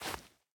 Minecraft Version Minecraft Version 25w18a Latest Release | Latest Snapshot 25w18a / assets / minecraft / sounds / block / powder_snow / break4.ogg Compare With Compare With Latest Release | Latest Snapshot